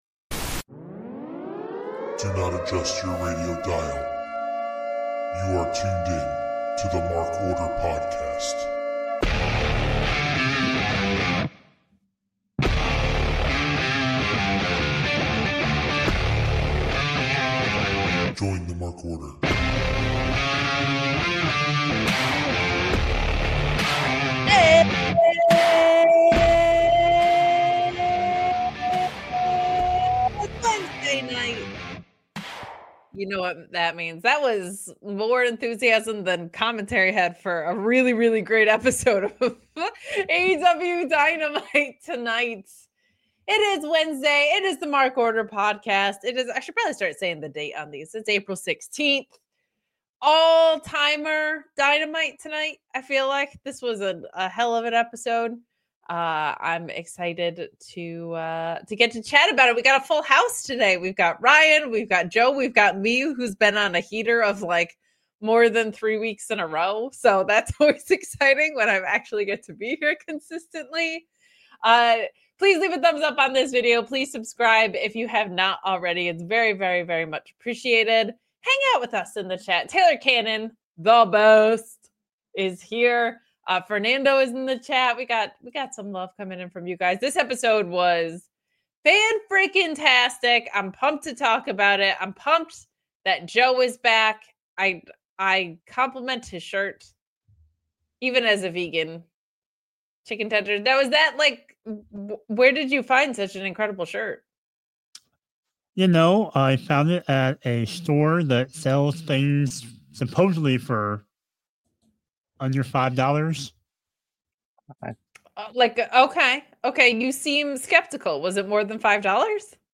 We have all three members of the Mark Order tonight! The group catches up and discusses the wrestling news cycle during this Mania week.